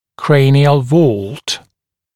[‘kreɪnɪəl vɔːlt][‘крэйниэл во:лт]свод черепа